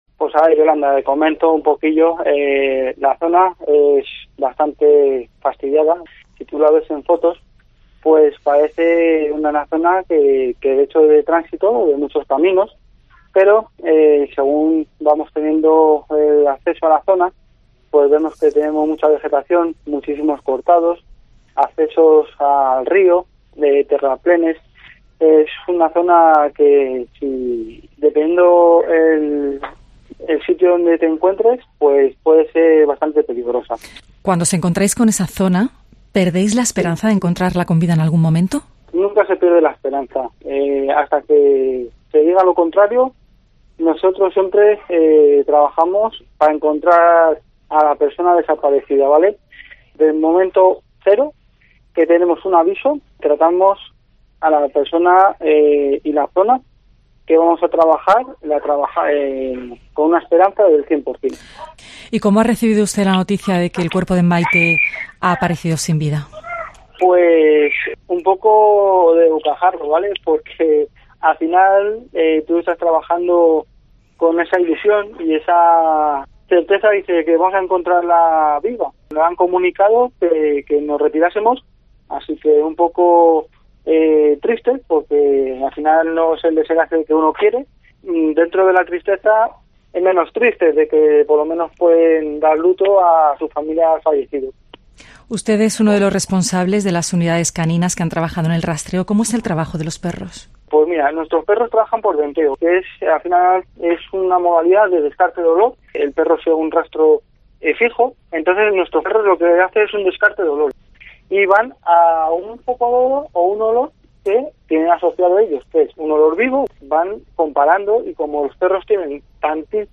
Escucha aquí el testimonio de uno de los responsables de las unidades caninas que ha trabajado en el dispositivo de búsqueda de la joven cordobesa